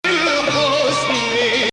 This performance actually uses the Zeffa rhythm typical in weddings.
Maqam Bayati
Performer: Mohamed Kheyri
Hijaz 4
Bayati Ah Ya Hilu 22 Hijaz.mp3